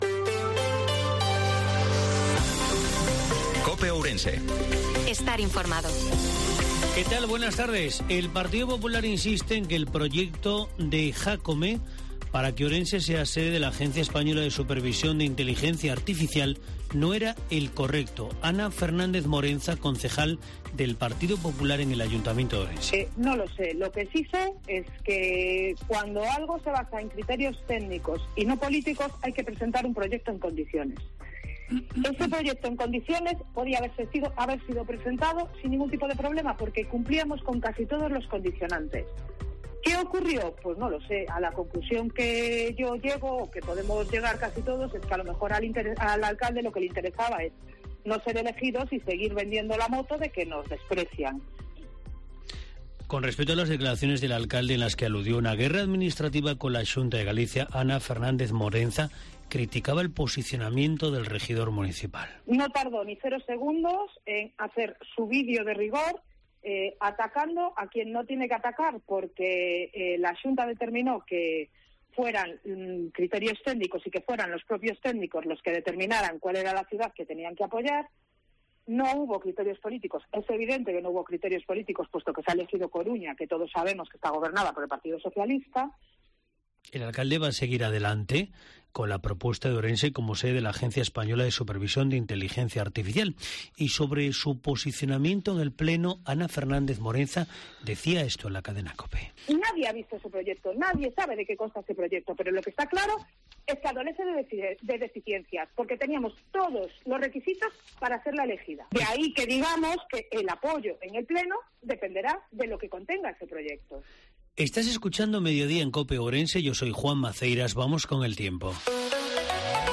INFORMATIVO MEDIODIA COPE OURENSE-26/10/2022